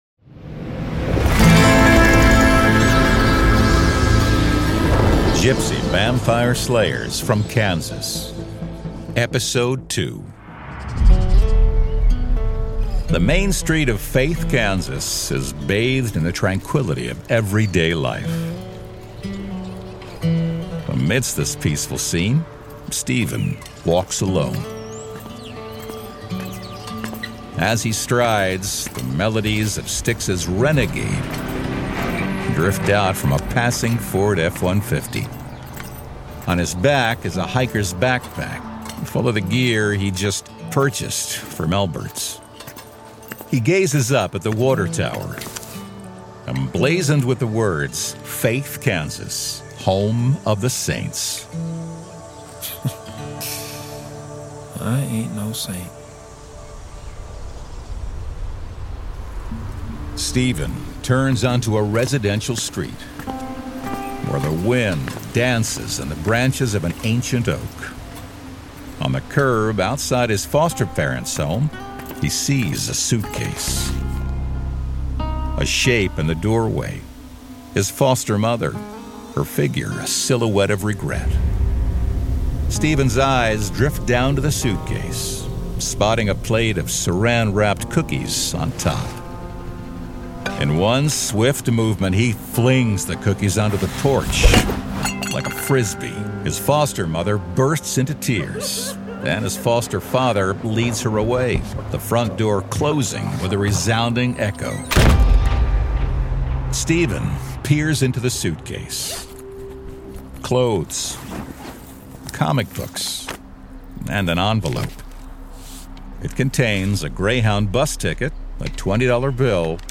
Format: Audio Drama
Voices: Full cast
Narrator: Third Person
Soundscape: Sound effects & music